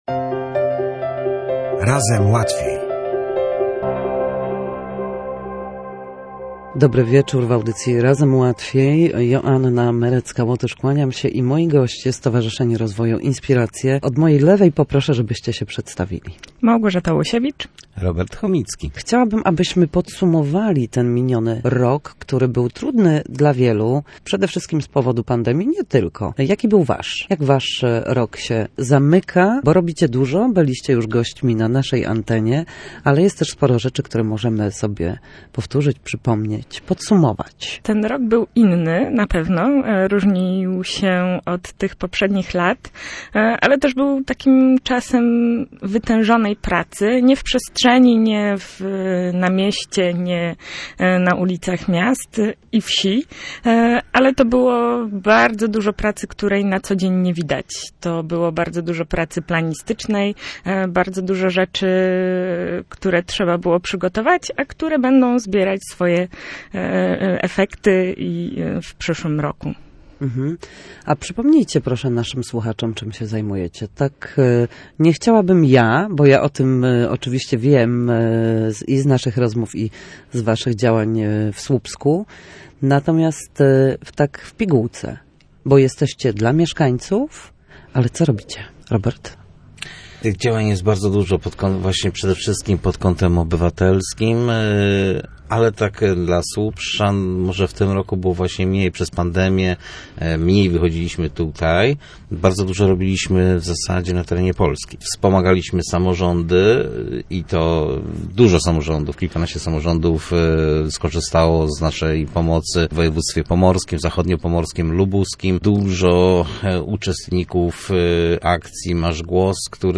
„Razem Łatwiej”: Dbają o bezpieczeństwo mieszkańców i rozliczają polityków. Rozmowa z członkami Stowarzyszenia Inspiracje